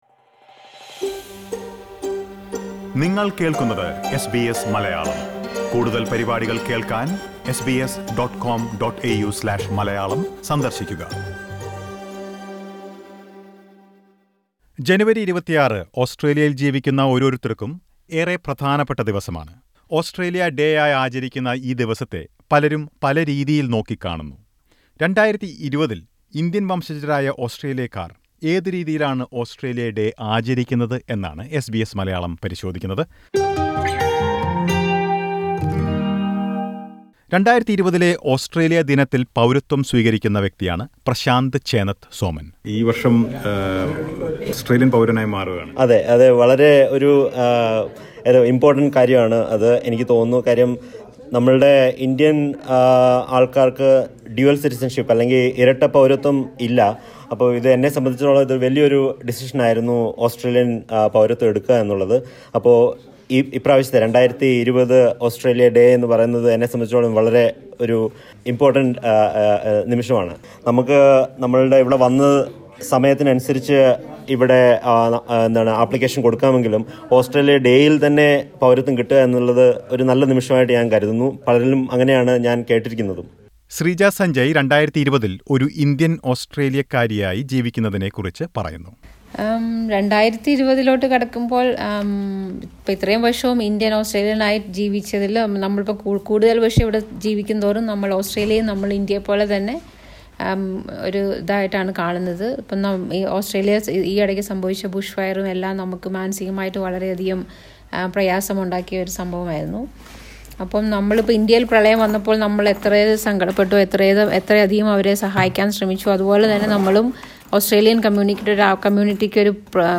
What does it mean to be an Indian Australian in 2020? SBS Malayalam speaks to some Australian Malayalees.